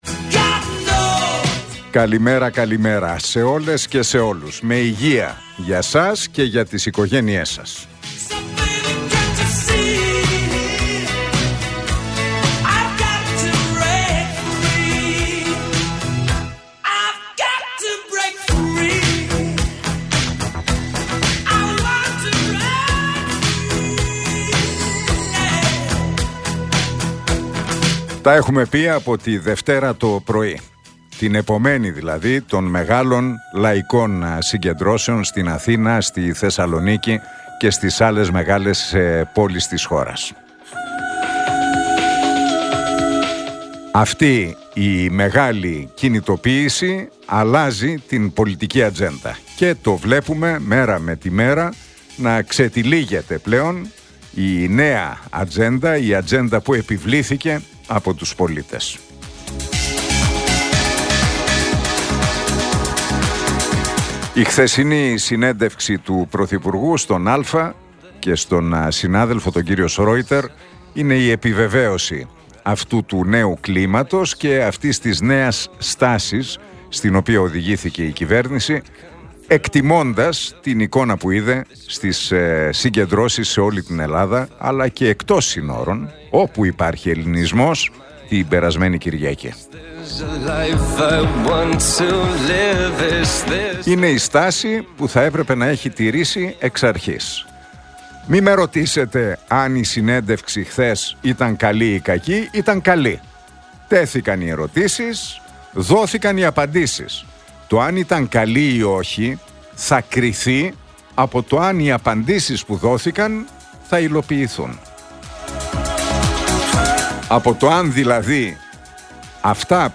Ακούστε το σχόλιο του Νίκου Χατζηνικολάου στον ραδιοφωνικό σταθμό RealFm 97,8, την Πέμπτη 30 Ιανουαρίου 2025.